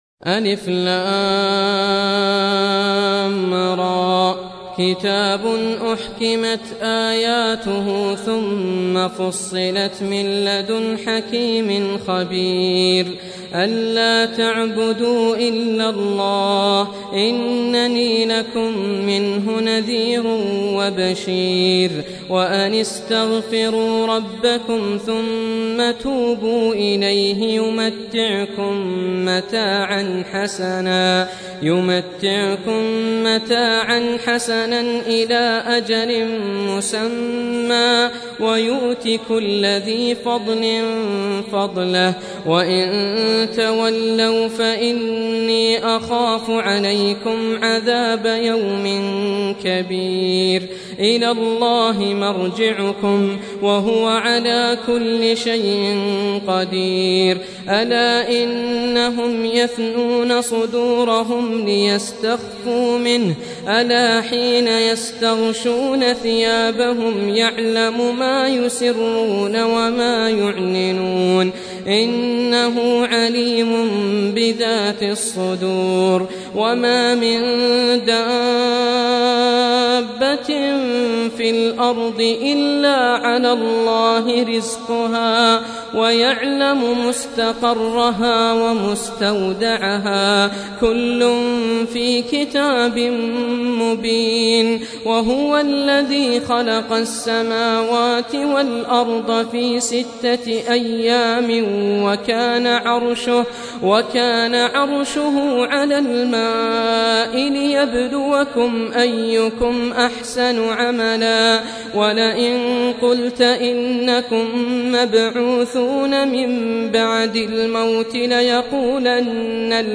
Surah Repeating تكرار السورة Download Surah حمّل السورة Reciting Murattalah Audio for 11. Surah H�d سورة هود N.B *Surah Includes Al-Basmalah Reciters Sequents تتابع التلاوات Reciters Repeats تكرار التلاوات